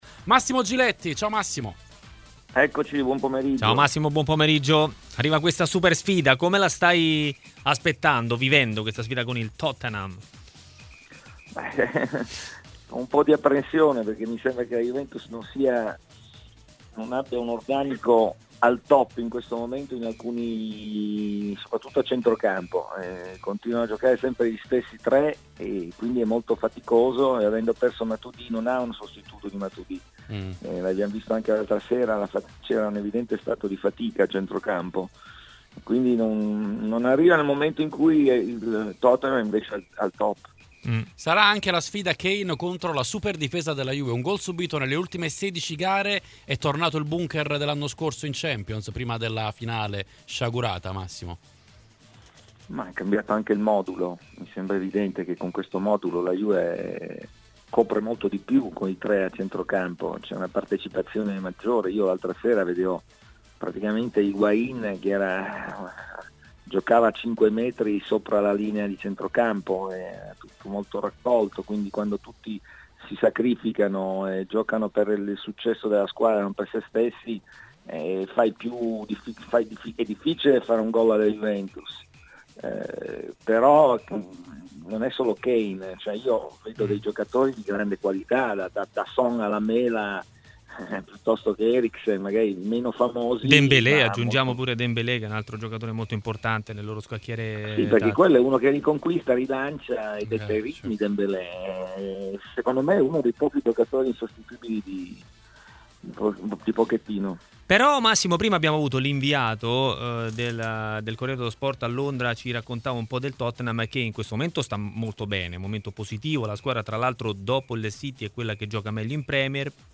Nel Maracanà pomeridiano di RMCSport è intervenuto un tifoso bianconero d'eccellenza, Massimo Giletti, per presentare la sfida di Champions della Juventus contro il Tottenham: "La squadra non deve sottovalutare gli inglesi e nello specifico non mi riferisco soltanto a Kane.
Massimo Giletti intervistato